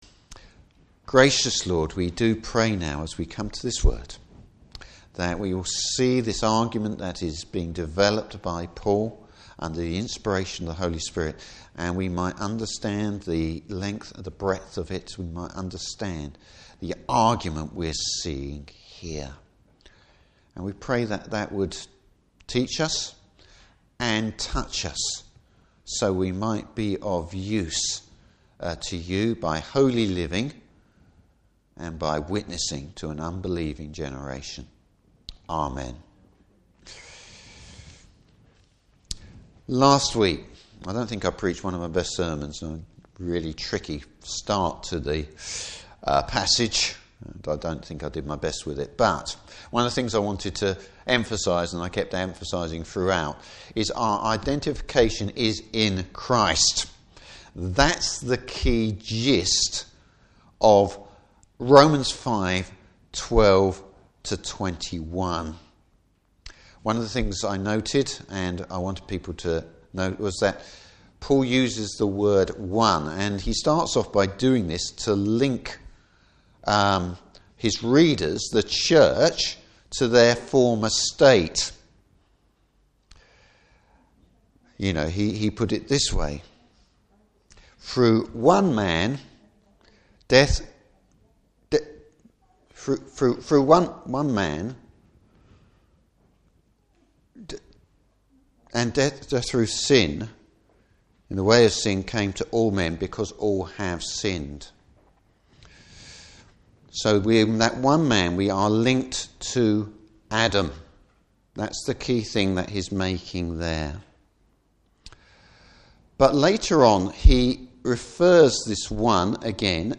Service Type: Morning Service What does it mean to die to sin?